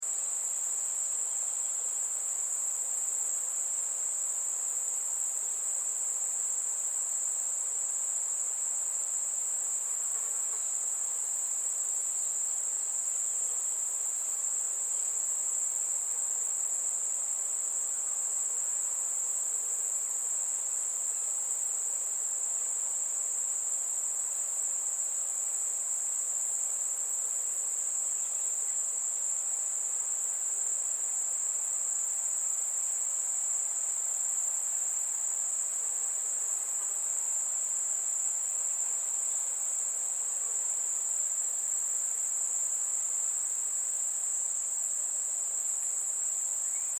دانلود آهنگ جنگل 5 از افکت صوتی طبیعت و محیط
جلوه های صوتی
دانلود صدای جنگل 5 از ساعد نیوز با لینک مستقیم و کیفیت بالا